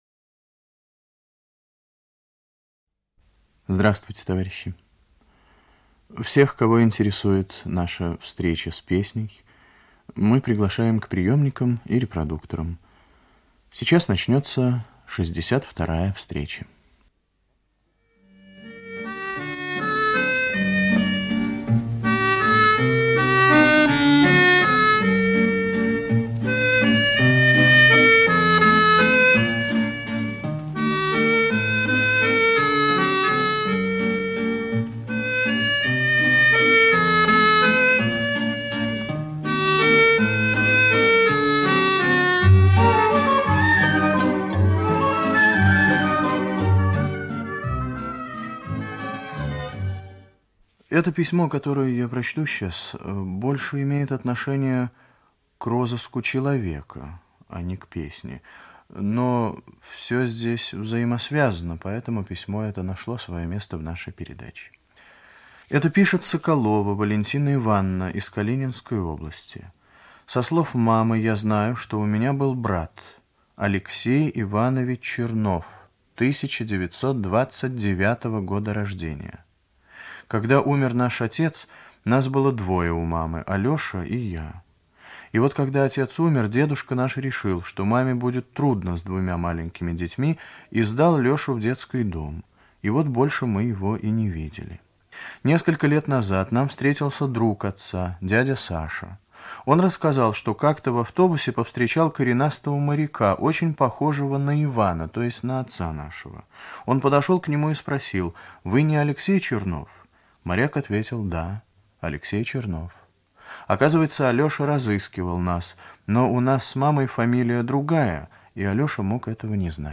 Ведущий - автор, Виктор Татарский.
Красноармейские частушки
Русская народная песня
Старинный русский романс
оркестр 4.
в сопровождении гитары